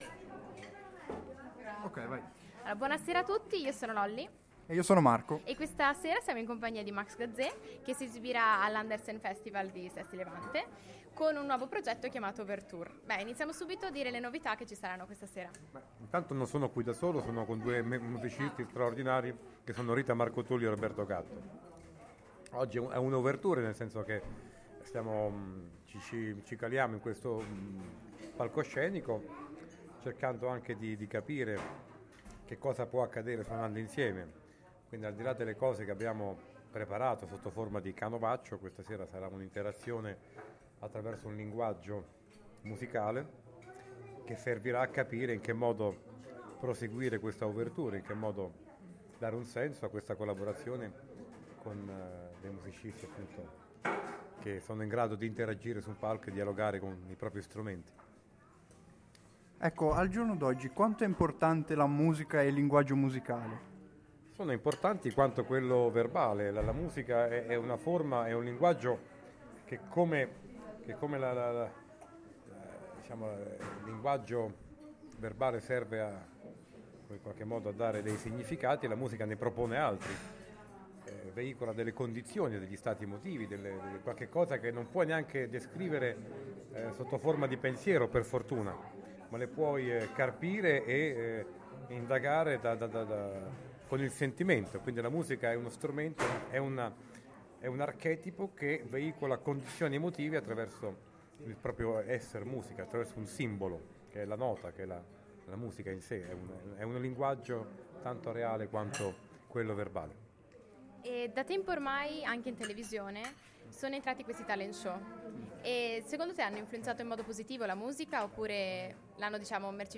Intervista a Max Gazzè
In occasione del Festival Andersen, abbiamo intervistato l'artista poliedrico Max Gazzè